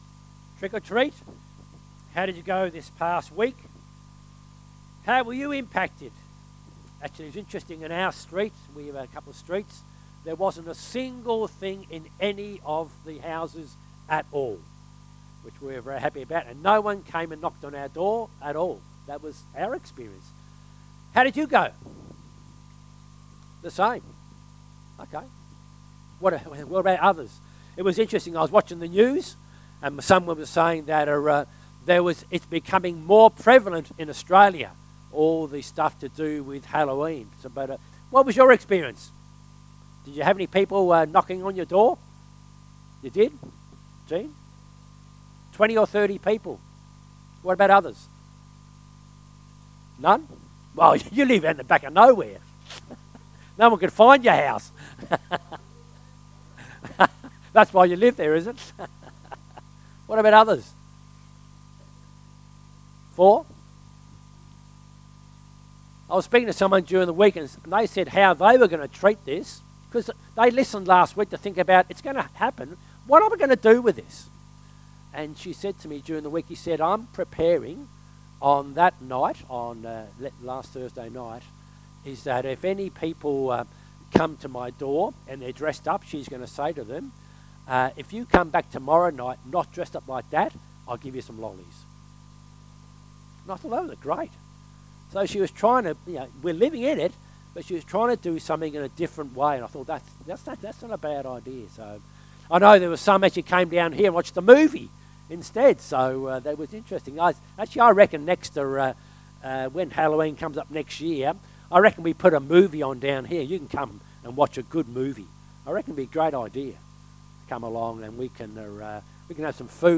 Sermon “Victory Over Darkness”